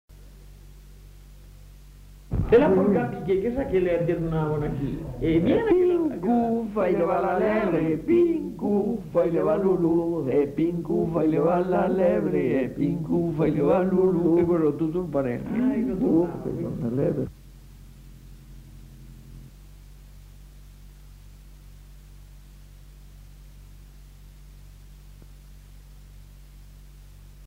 Aire culturelle : Haut-Agenais
Genre : chant
Type de voix : voix mixtes
Production du son : chanté
Danse : polka piquée